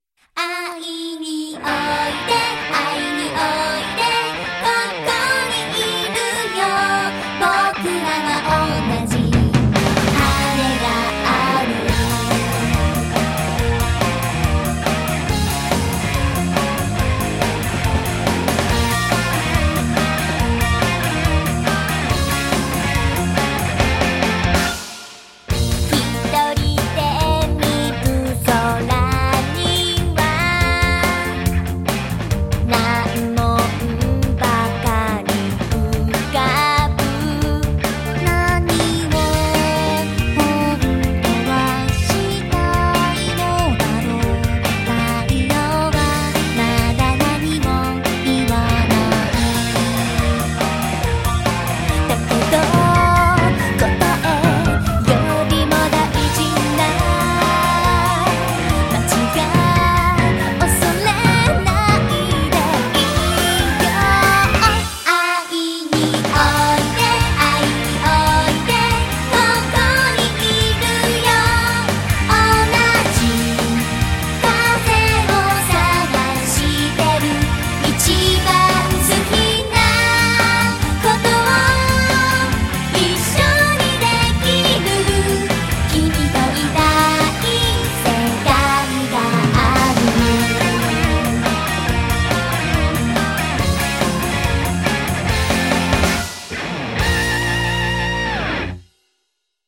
normalized to -0dB